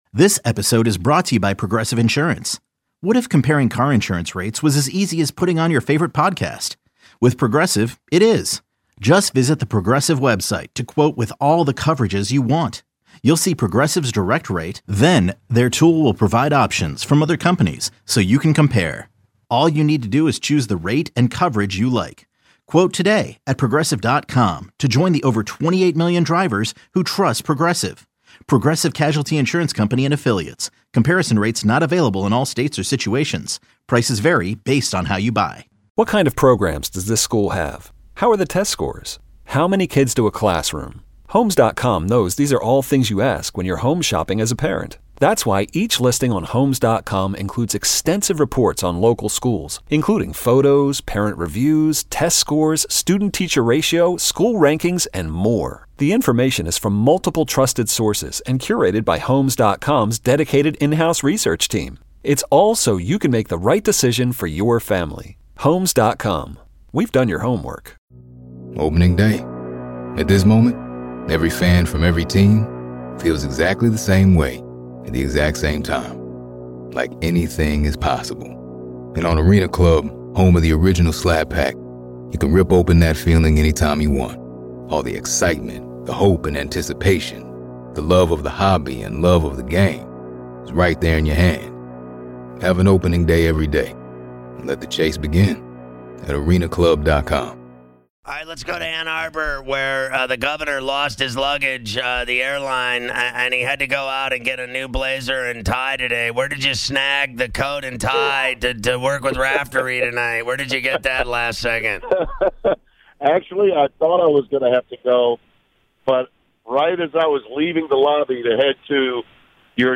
11-12-19- Ferrall on the Bench- Tim Brando Interview